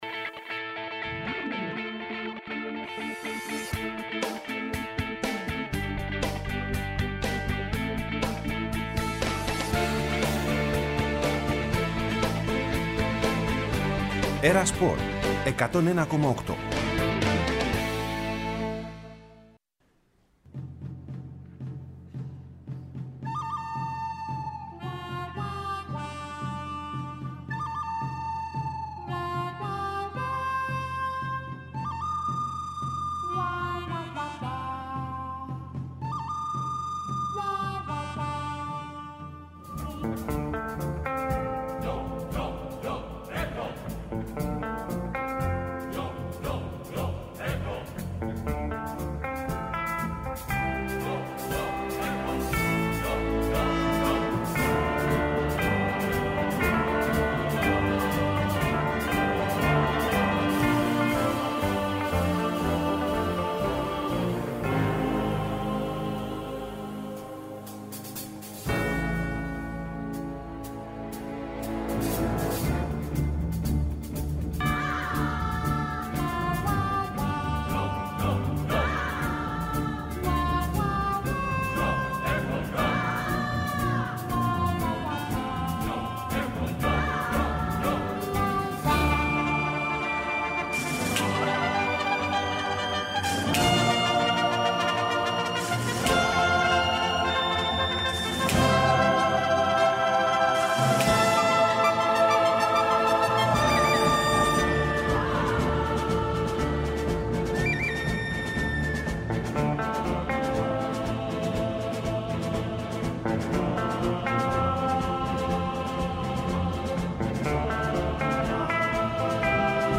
Ενημέρωση από τους ρεπόρτερ του Ολυμπιακού, του Παναθηναϊκού, της ΑΕΚ, του ΠΑΟΚ και του Άρη.